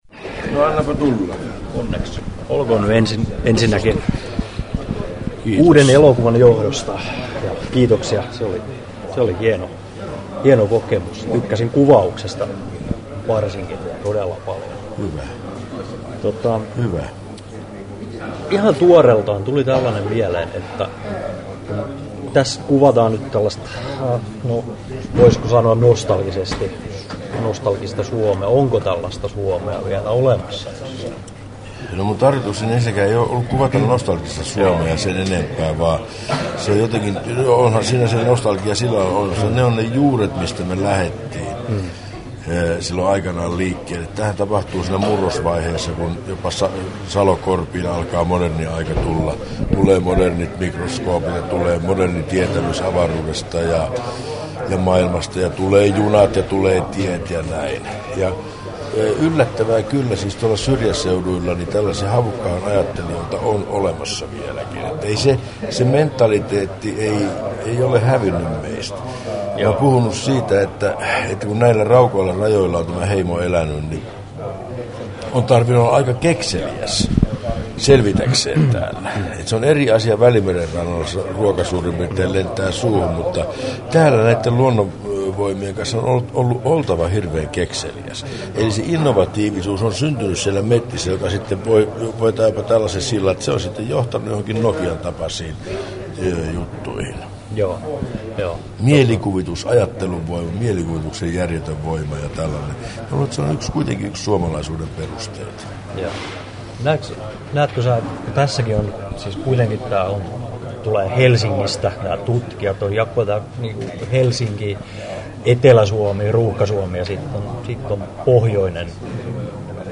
Kari Väänäsen haastattelu Kesto: 17'22" Tallennettu: 3.12.2009, Turku Toimittaja